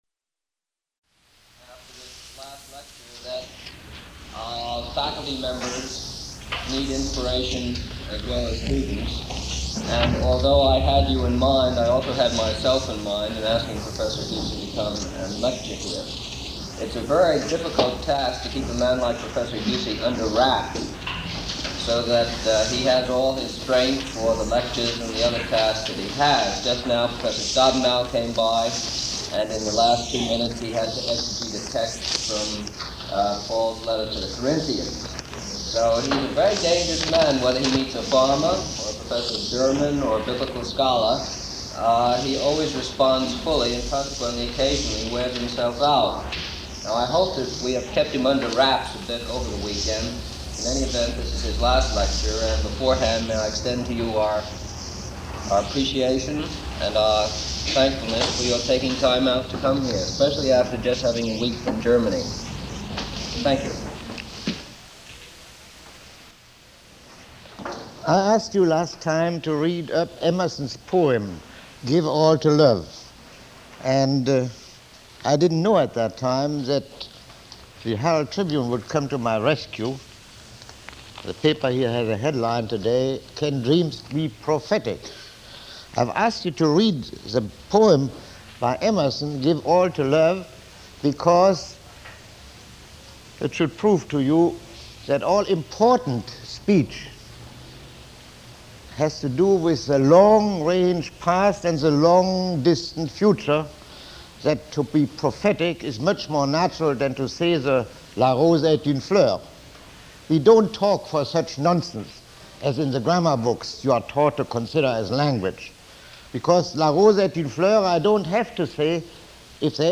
Lecture 03